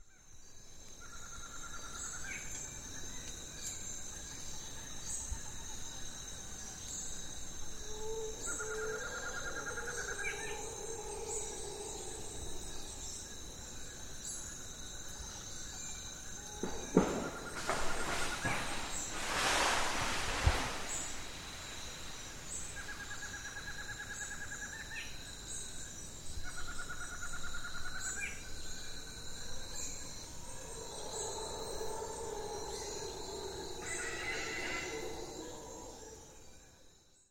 Then I went for a walk in the woods and turned the recorder on to capture bird sounds.
branchfall.mp3